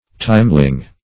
timeling - definition of timeling - synonyms, pronunciation, spelling from Free Dictionary Search Result for " timeling" : The Collaborative International Dictionary of English v.0.48: Timeling \Time"ling\ (t[imac]m"l[i^]ng), n. A timeserver.